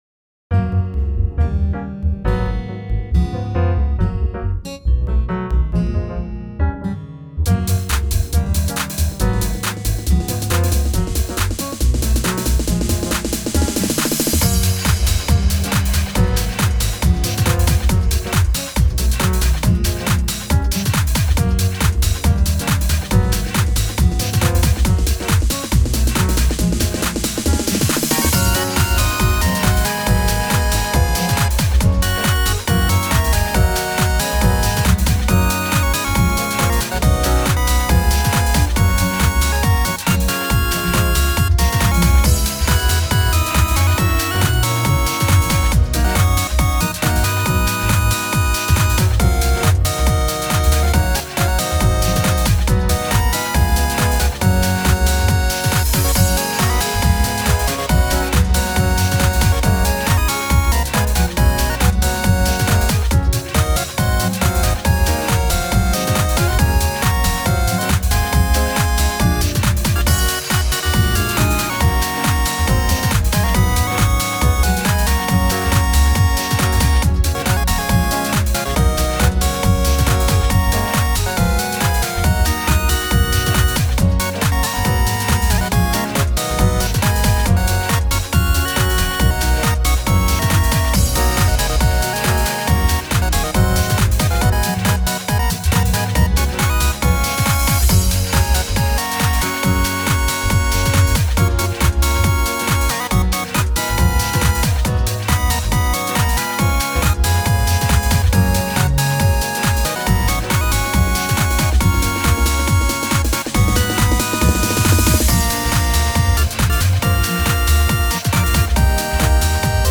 Instrumenal House